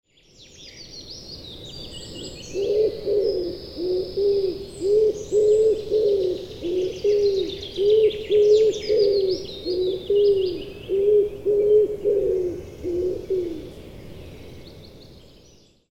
Digiloto - Linnulaulumäng
Вяхирь
kaelustuvi.mp3